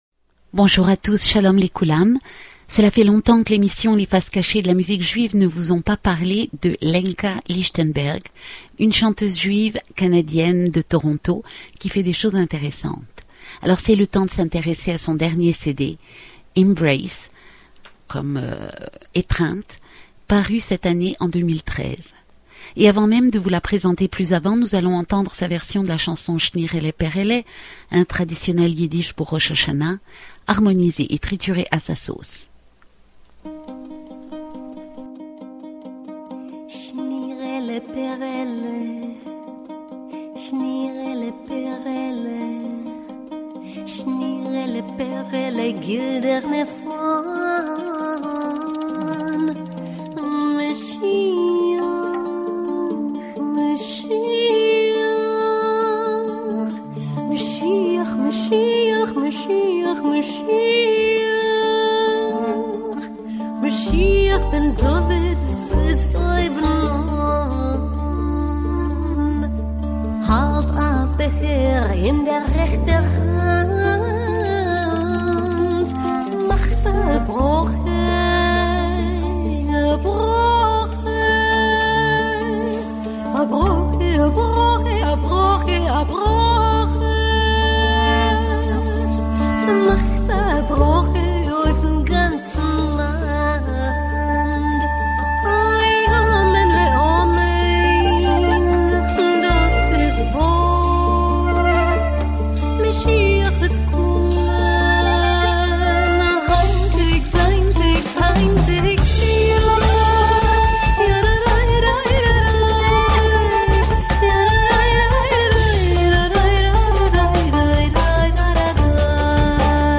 A radio program